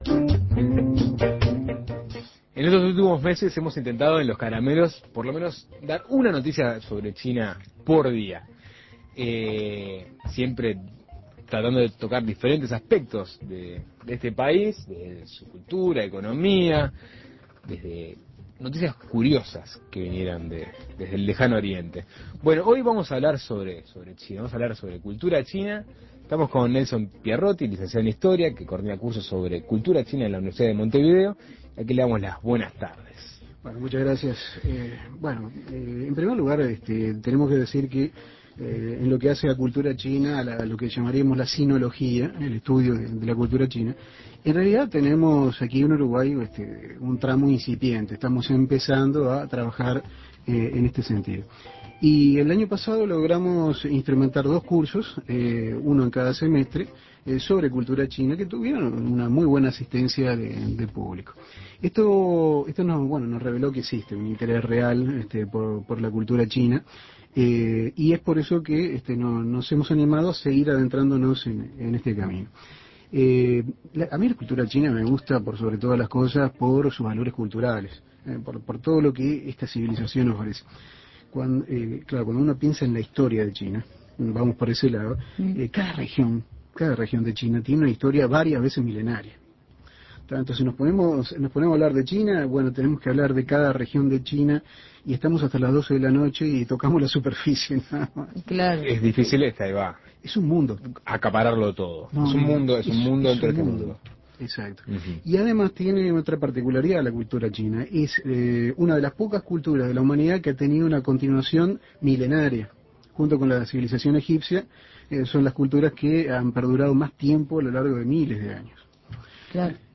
Entrevistas China